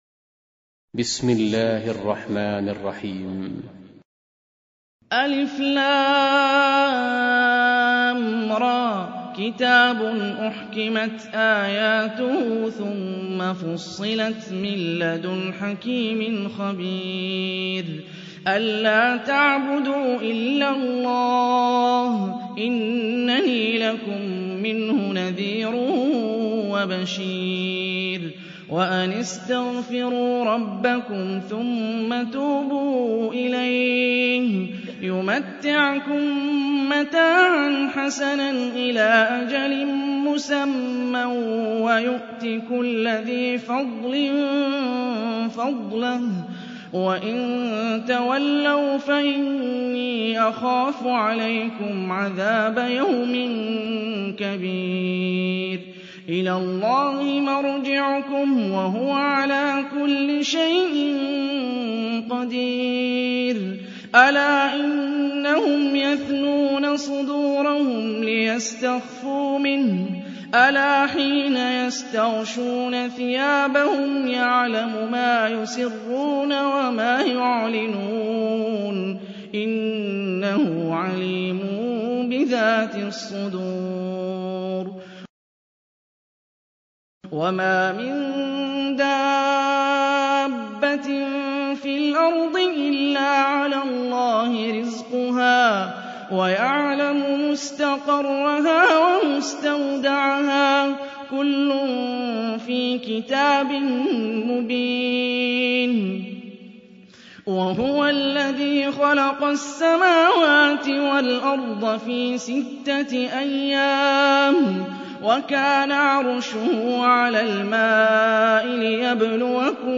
Tarteel Recitation
Surah Repeating تكرار السورة Download Surah حمّل السورة Reciting Murattalah Audio for 11. Surah H�d سورة هود N.B *Surah Includes Al-Basmalah Reciters Sequents تتابع التلاوات Reciters Repeats تكرار التلاوات